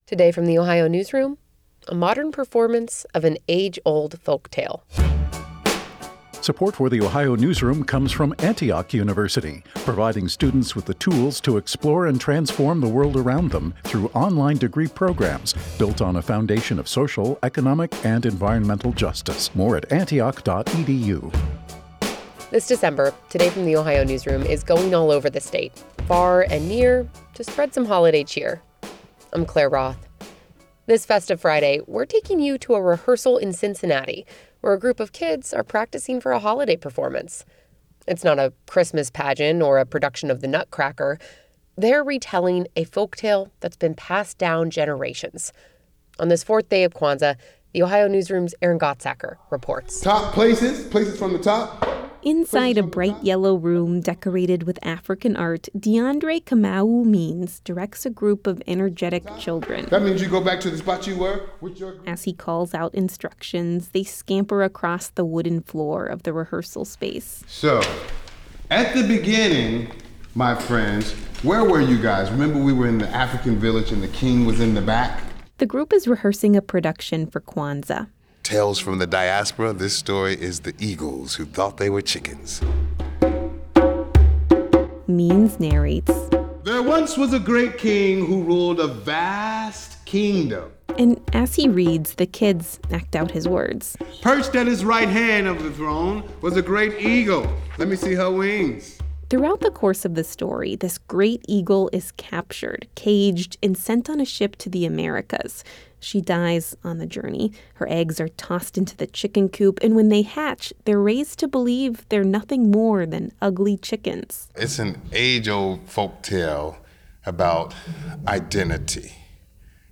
This Thursday afternoon, the group is getting ready for a timeless holiday performance.
The story is an African folktale told using traditional African instruments.
The kids wave their arms and leap from side to side as they sing.